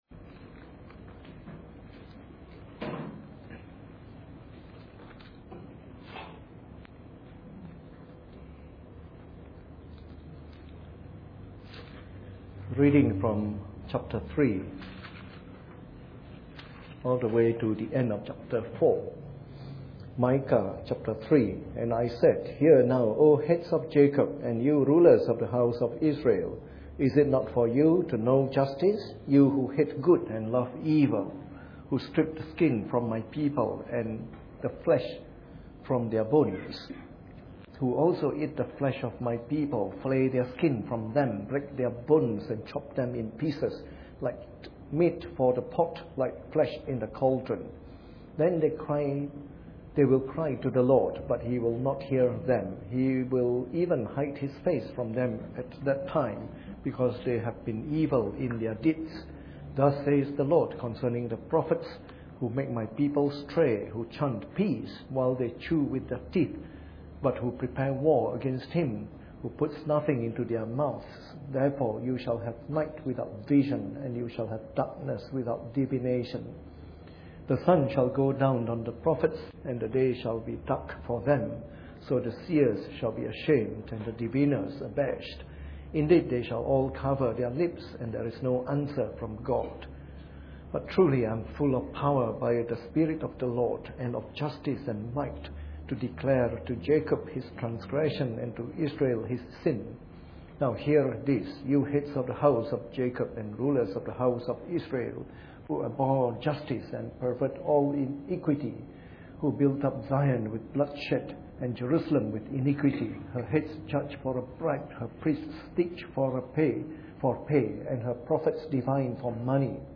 Preached on the 2nd of January 2013 during the Bible Study, from our series on “The Minor Prophets.”